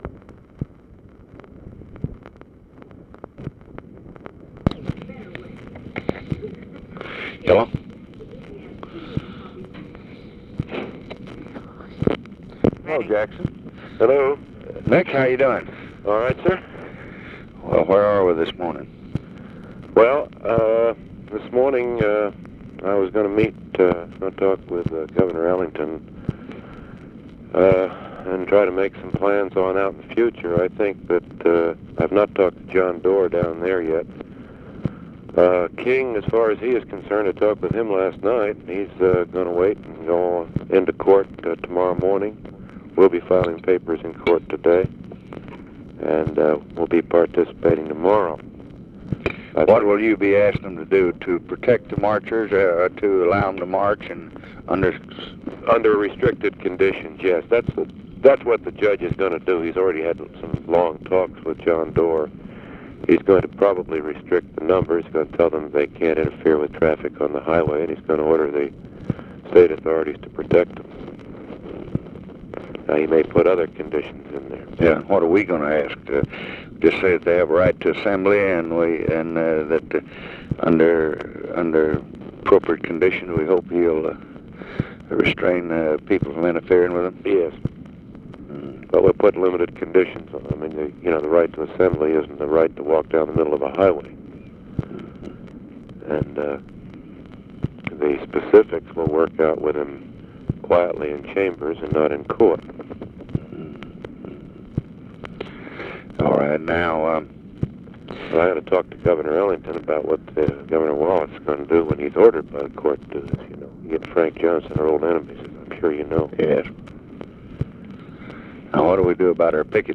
Conversation with NICHOLAS KATZENBACH, March 10, 1965
Secret White House Tapes